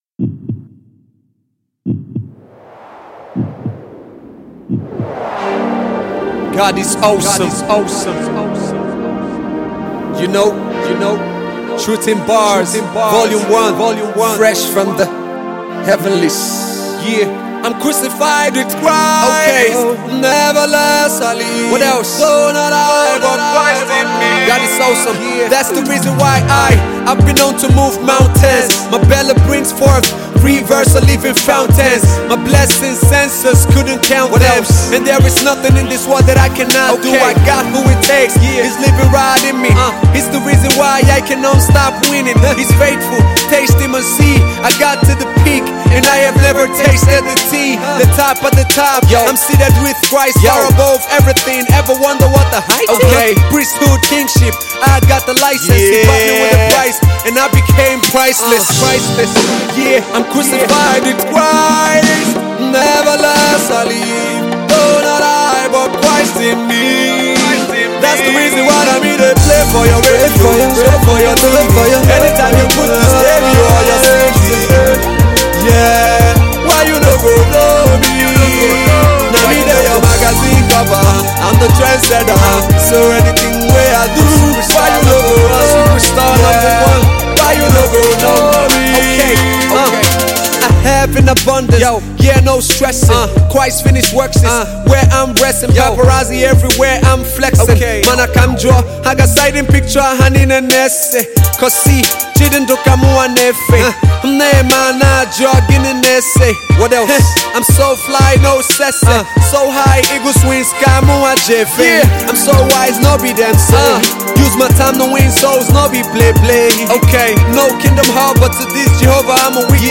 Rapper cum singer